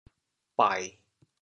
潮阳拼音“bai1”的详细信息
bai1.mp3